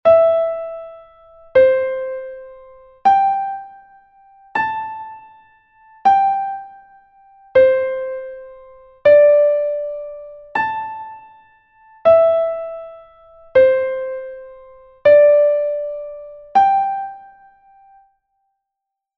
note recognition exercise 4